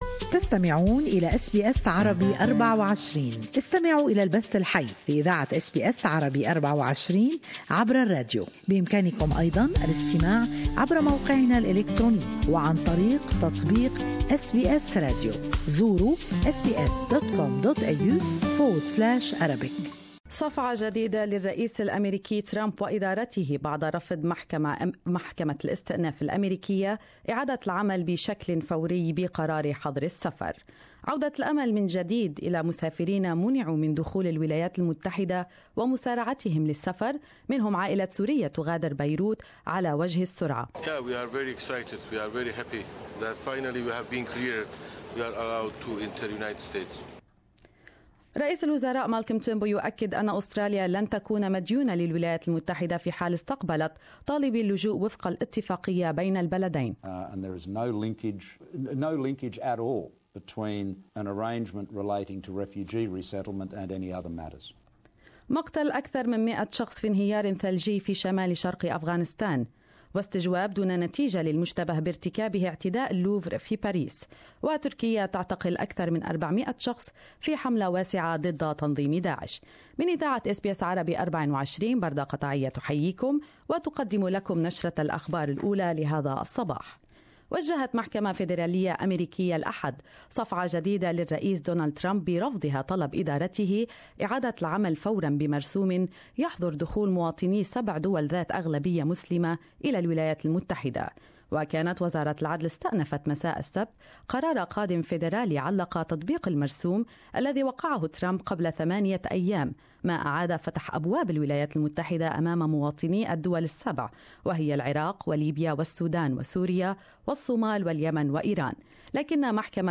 News Bulletin 6-02-17